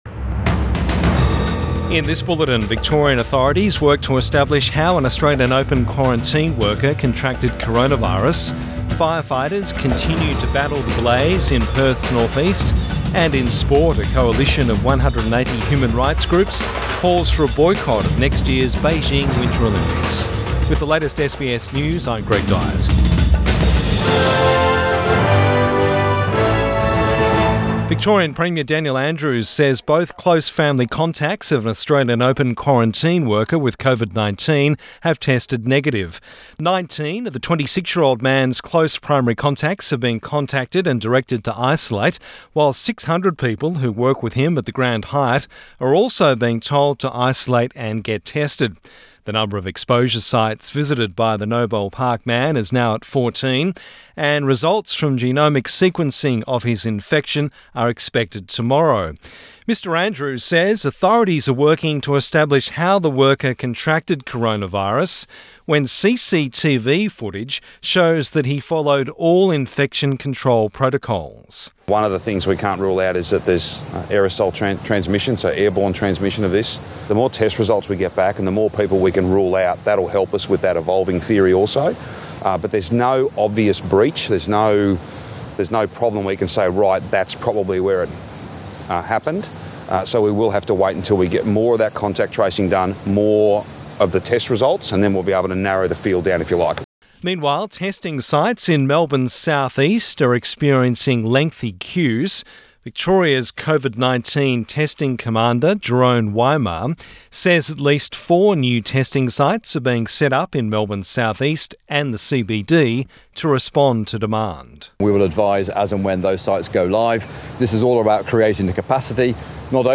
Midday bulletin 4 February 2021